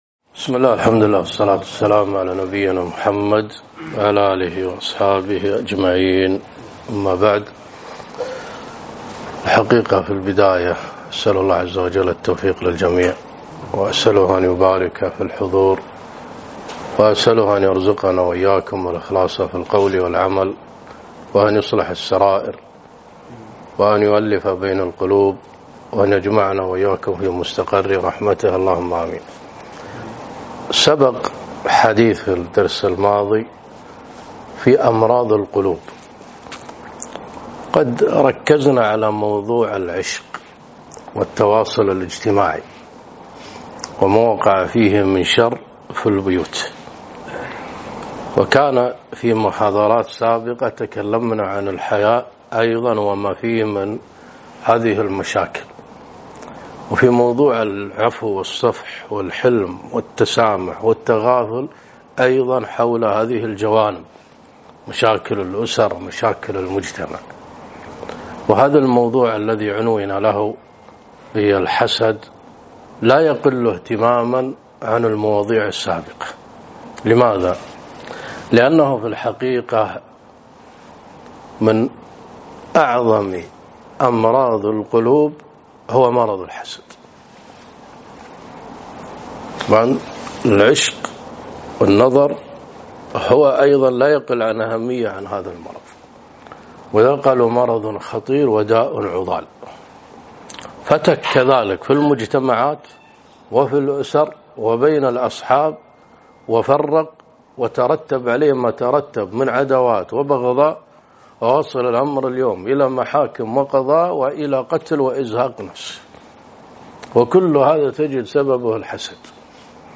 محاضرة - الحسد وأسبابه وعلاجه